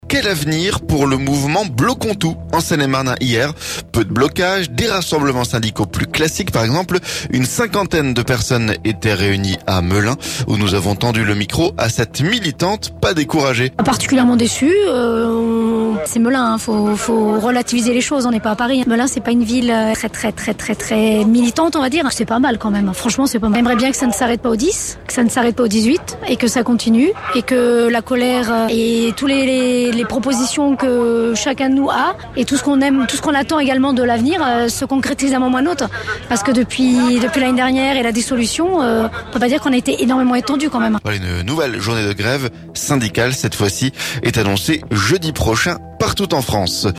Où nous avons tendu le micro à cette militante, pas découragée.